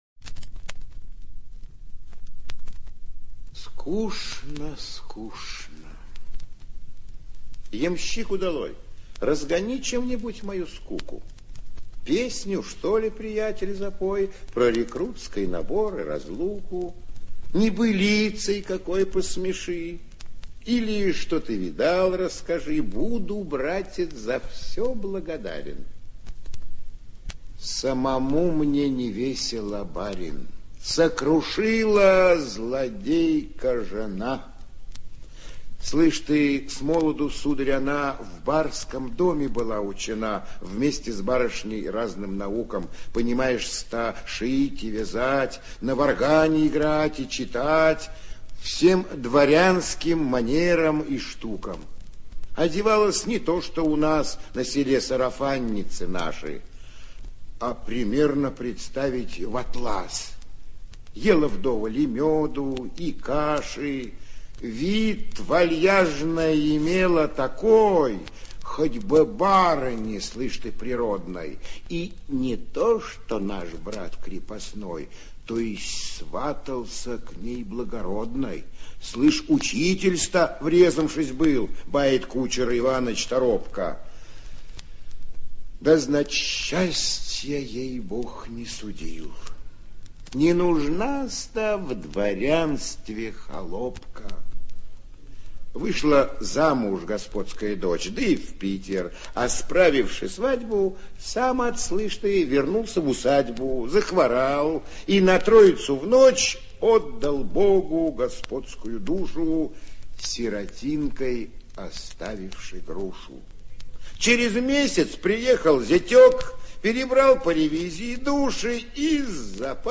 6. «Н.А. Некрасов – В дороге, (чит. А. Грибов)» /